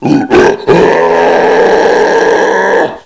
assets/psp/nzportable/nzp/sounds/zombie/r0.wav at 29b8c66784c22f3ae8770e1e7e6b83291cf27485